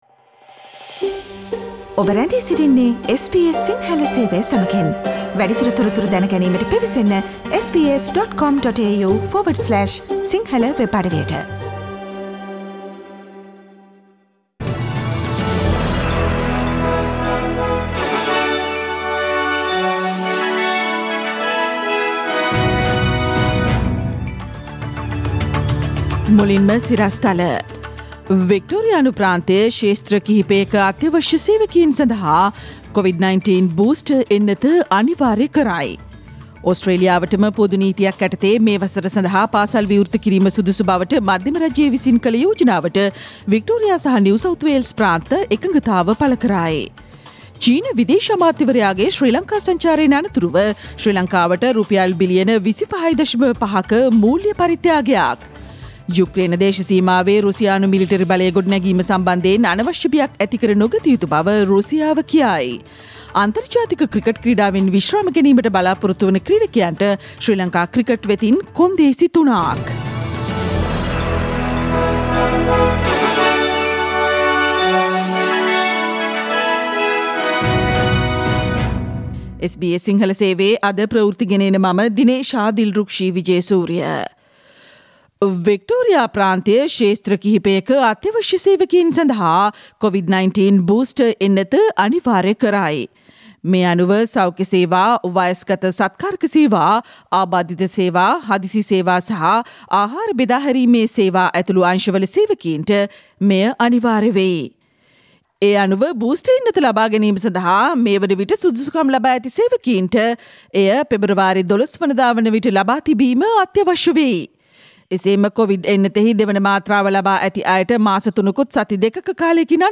Click on the speaker icon on the image above to listen to the SBS Sinhala Radio news bulletin on Tuesday 11 January 2022